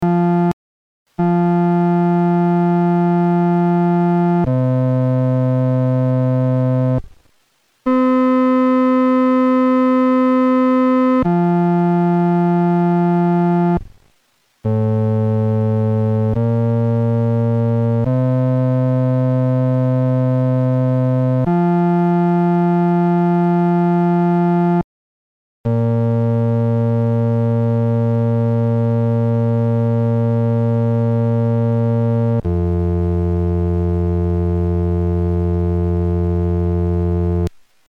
男低